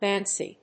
発音記号・読み方
/ˈ-- m`ænsi(米国英語)/